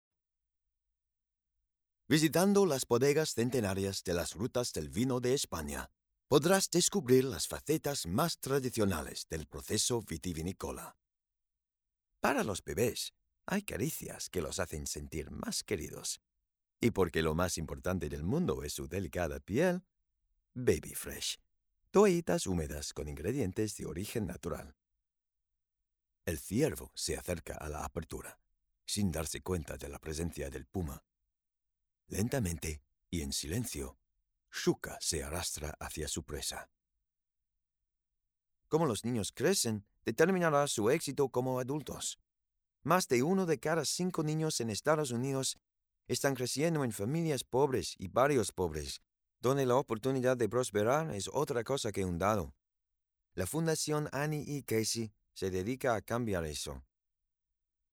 SPANISH DEMO SHOWREEL
Male